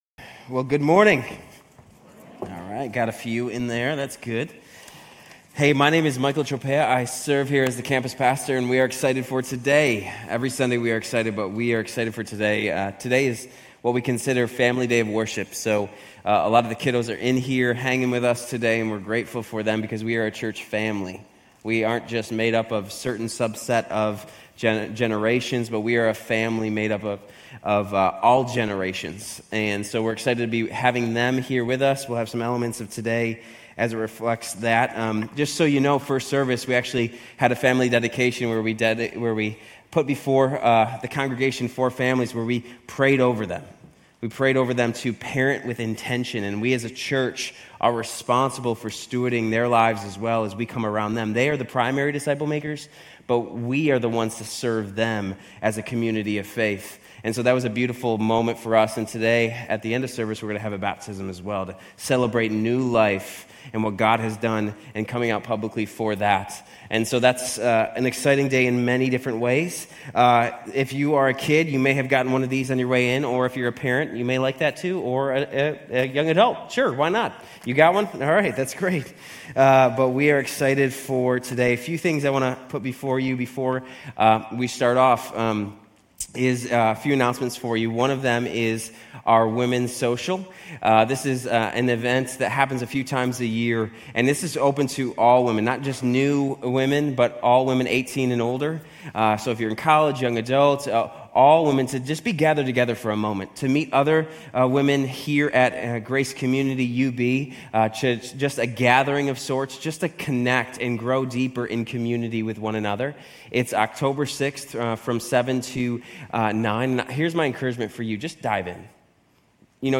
Grace Community Church University Blvd Campus Sermons Genesis 6:5 - Noah Sep 29 2024 | 00:35:42 1x 00:00 / 00:35:42 Subscribe Share RSS Feed Share Link Embed